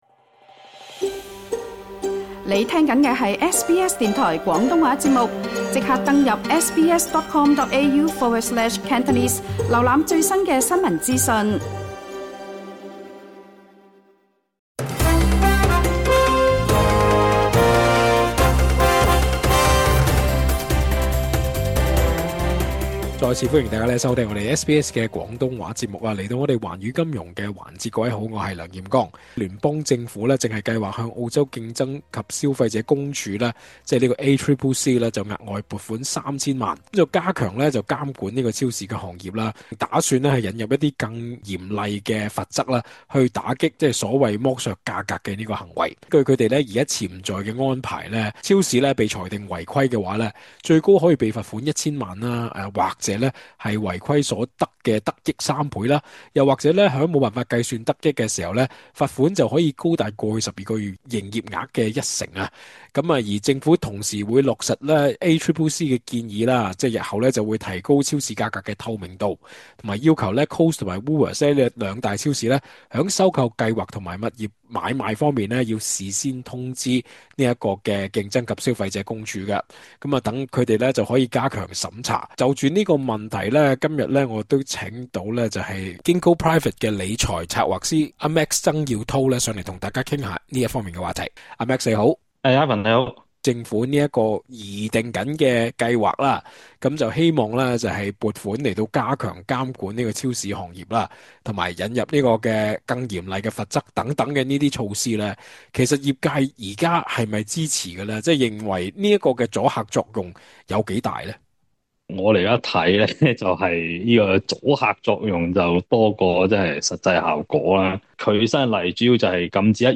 收聽足本訪問： LISTEN TO 【政府嚴打超市抬價】加強審查反而變相抬高物價？